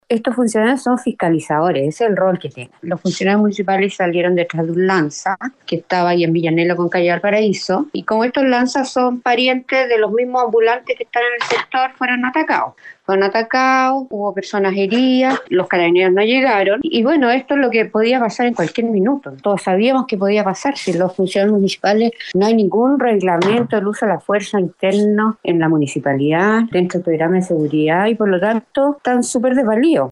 Por otra parte, Antonella Pecchenino, concejala del Partido Republicano, indicó que este ataque fue provocado por ambulantes que mantienen una relación estrecha con los delincuentes del sector.